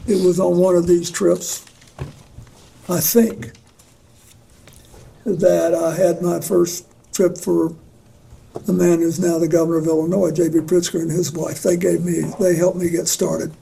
During a U.S. House Oversight Committee hearing last Friday, former President Bill Clinton was asked who he normally brought on Epstein-connected flights.